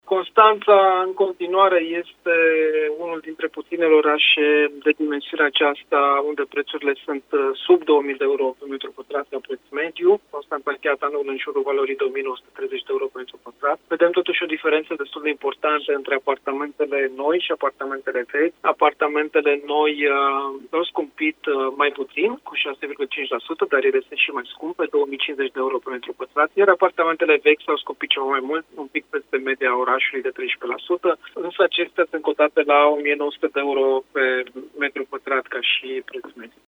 reprezentantul unei agenții imobiliare